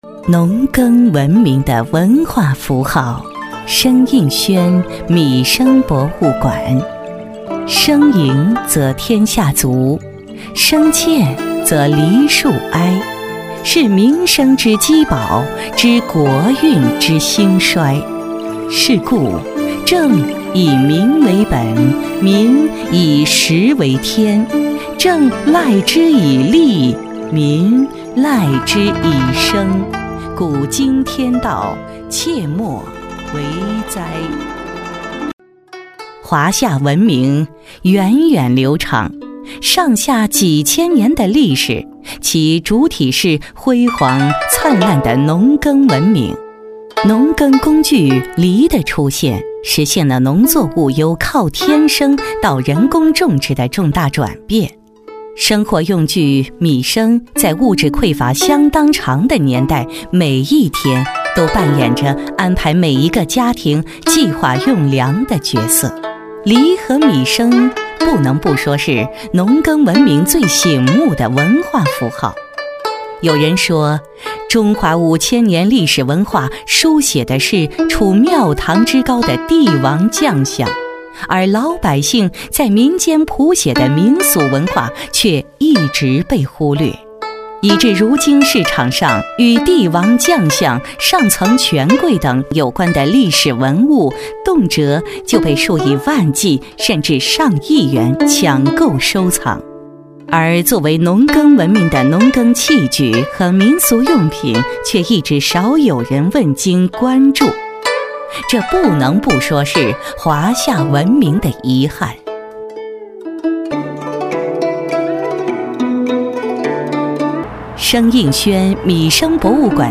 专注高端配音，拒绝ai合成声音，高端真人配音认准传音配音
女02